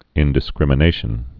(ĭndĭ-skrĭmə-nāshən)